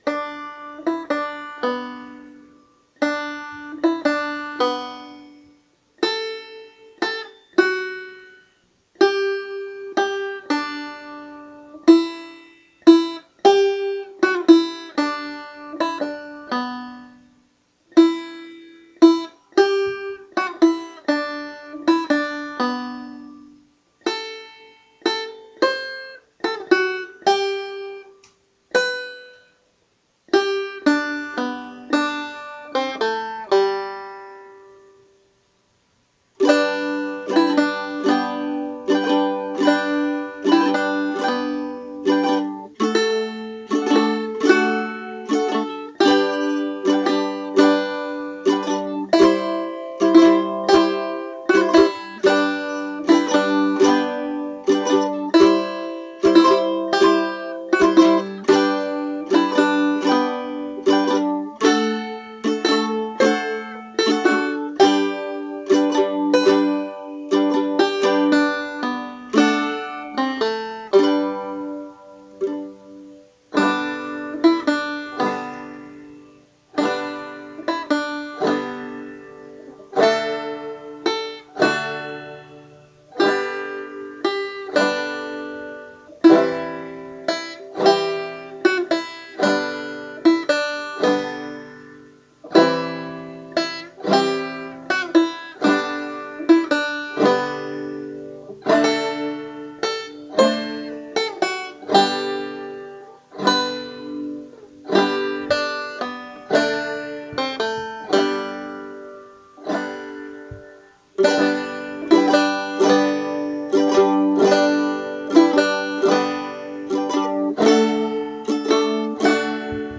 banjo and mandolin
Words: Joseph Mohr, 1818; trans. John Freeman Young, 1863
Music: STILLE NACHT, Franz Xaver Gruber, 1818